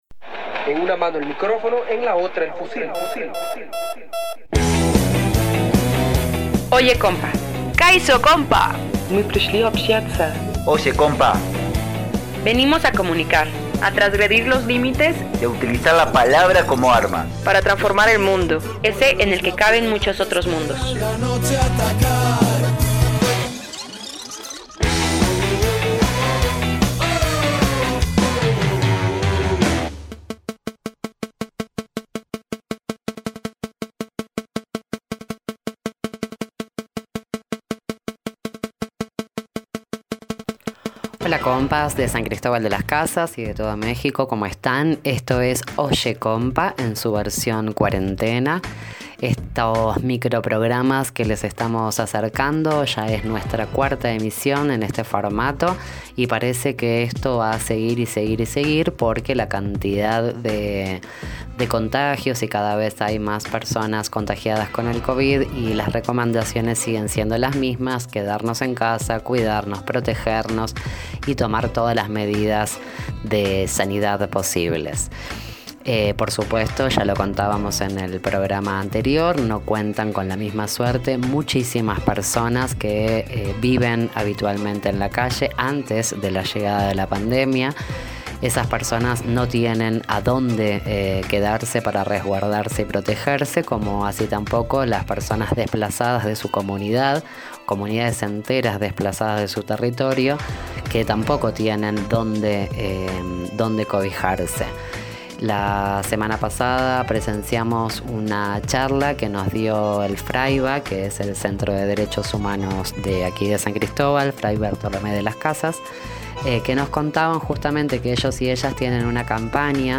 Presentación de Tierra Roja Cuxtitali Centro Comunitario A.C. en el marco del seminario virtual de Amigos de San Cristóbal, Mayo 2020